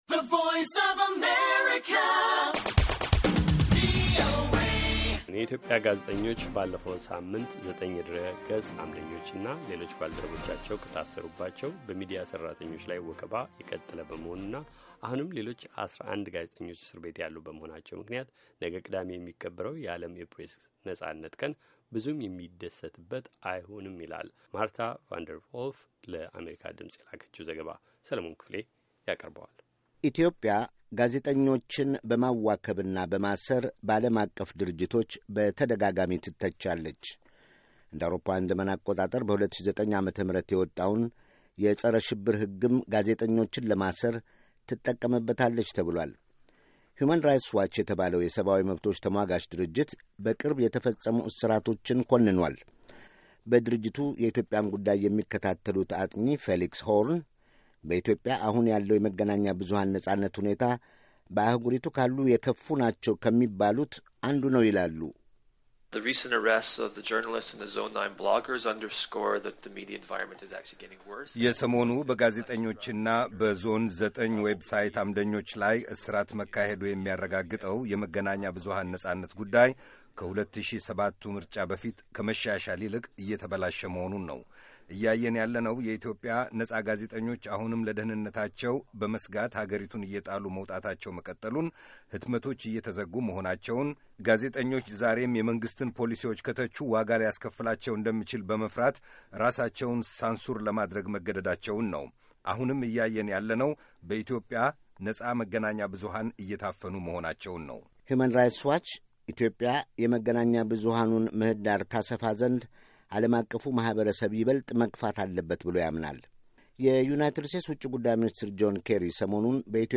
ከአዲስ አበባ ለቪኦኤ የላከችው ዘገባ